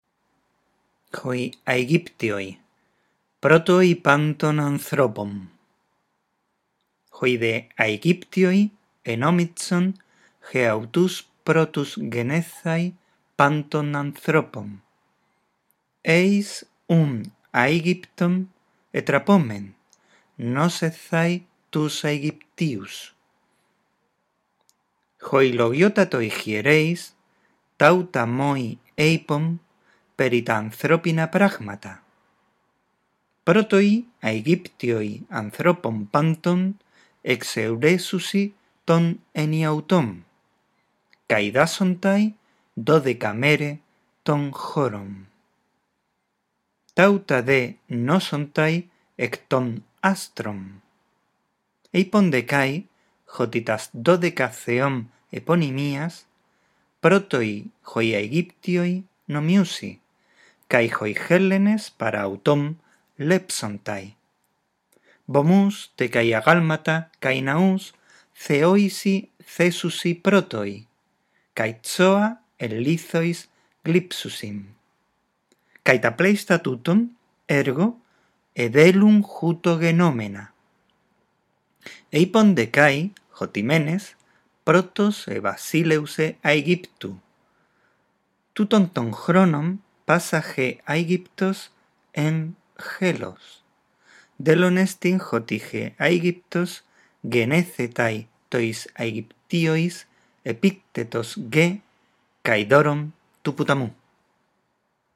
Lee el texto en voz alta, respetando los signos de puntuación.